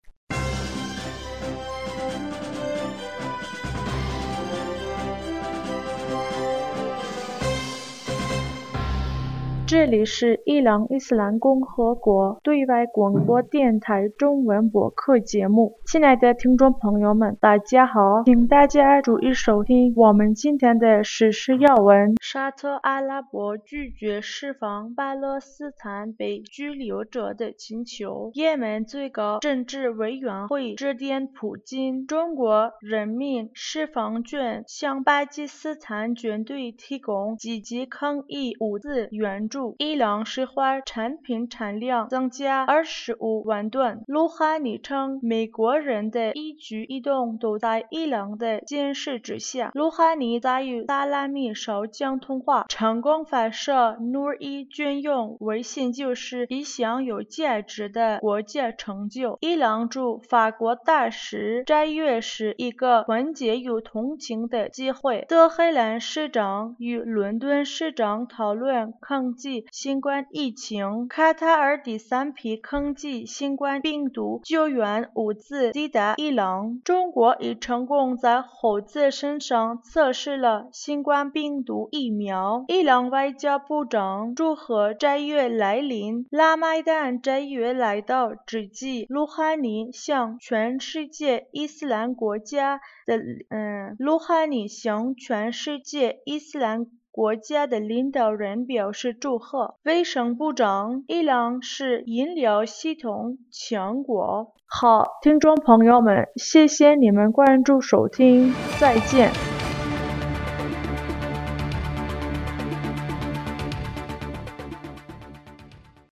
2020年 4月 25日 新闻